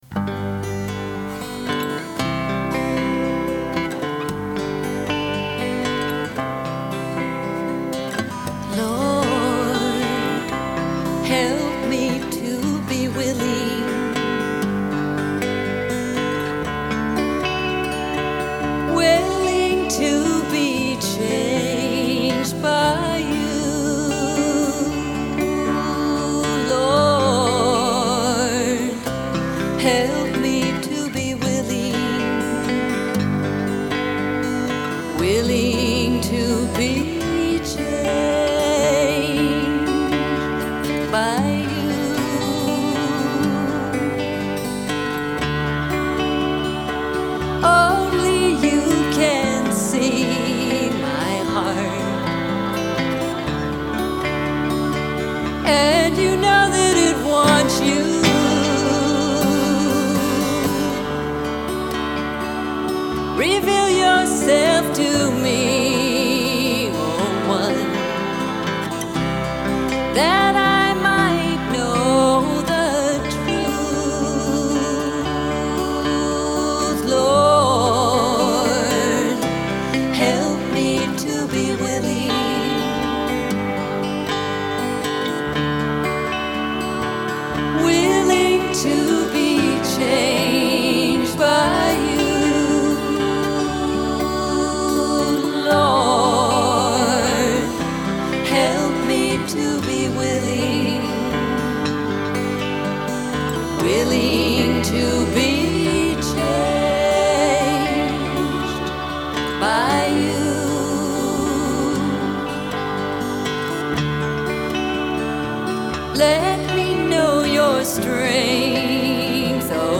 1. Devotional Songs
Major (Shankarabharanam / Bilawal)
Medium Slow
2 Pancham / D
6 Pancham / A
Lowest Note: d2 / A (lower octave)
Highest Note: S / C (higher octave)